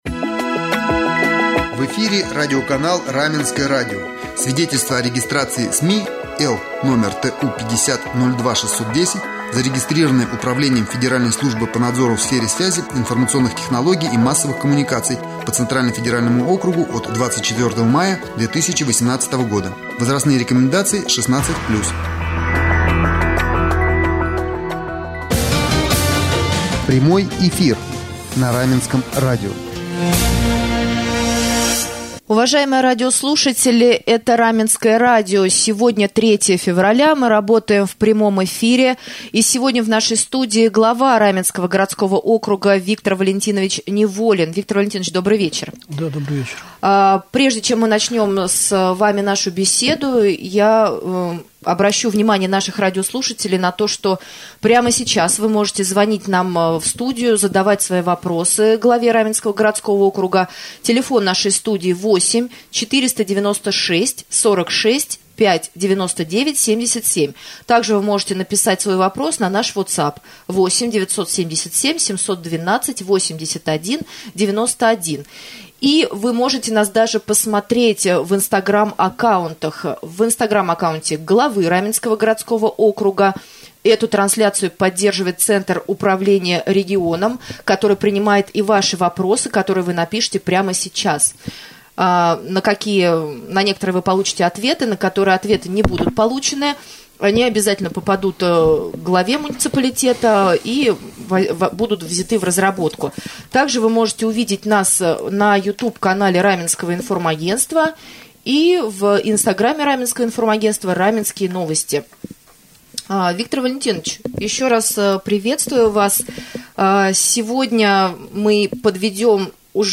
Глава Раменского городского округа Виктор Валентинович Неволин стал гостем прямого эфира на Раменском радио 3 февраля